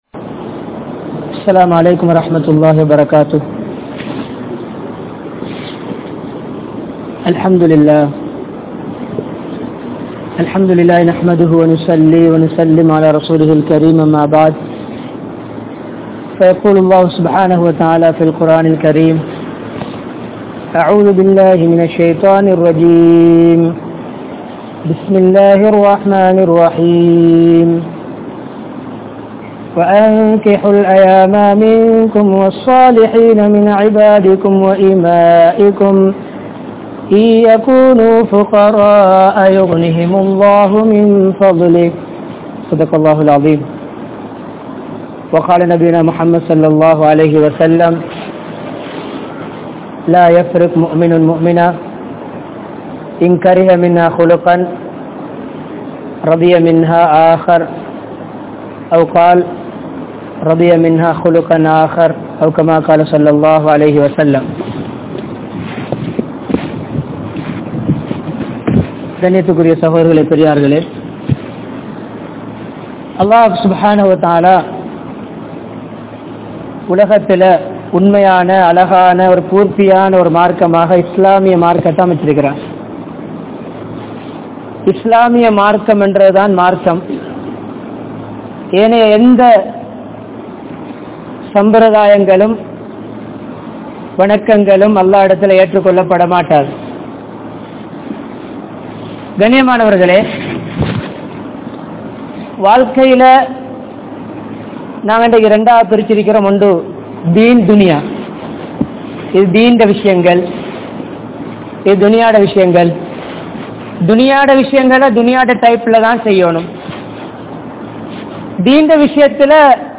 Wellampitiya, Polwatte, Masjidun Noor Jumua Masjidh